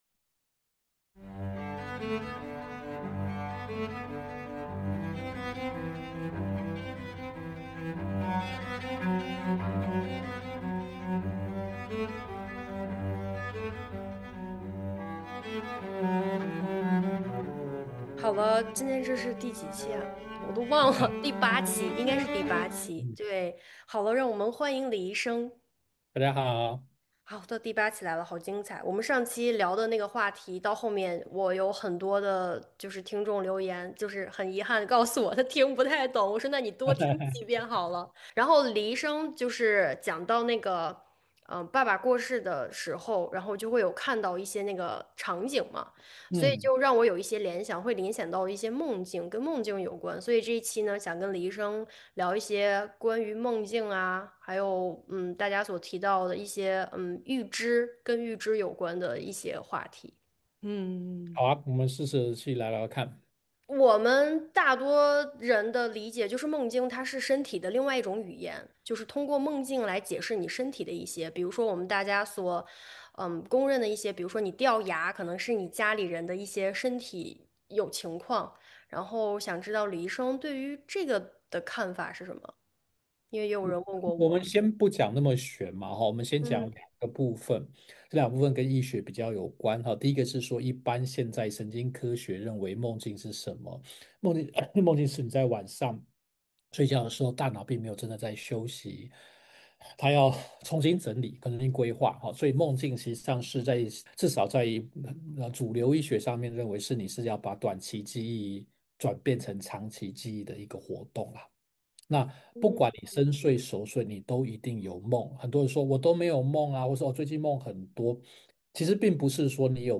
目前沒有特定的題材，也沒有特定的時間表，隨性也隨時間，藉由主持人的提問，來和大家聊一聊。